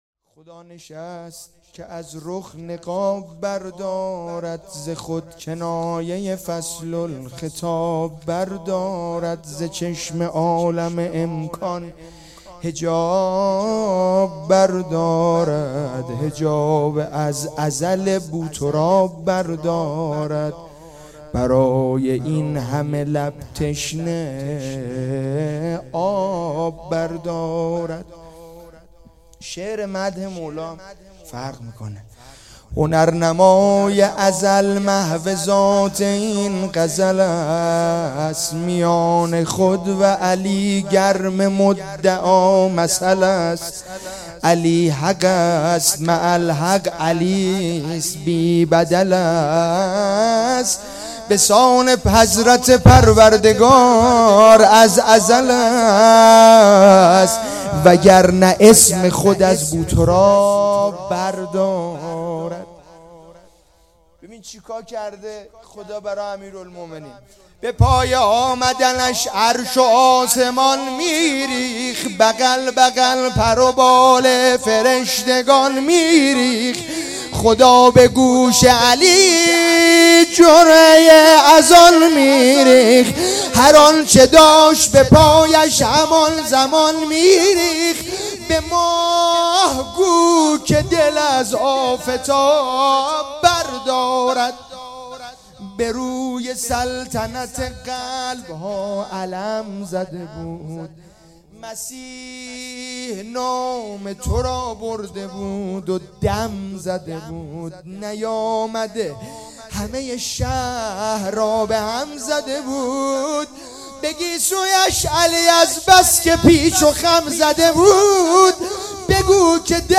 جشن ولادت امیرالمومنین امام علی علیه السلام